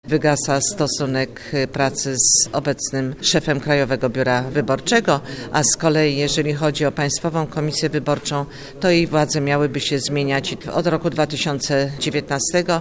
– We wtorek komisja zarekomendowała między innymi artykuły dotyczące wygaśnięcia kadencji członków Państwowej Komisji Wyborczej oraz szefa Krajowego Biura Wyborczego – mówi przewodnicząca komisji Anna Milczanowska z Prawa i Sprawiedliwości.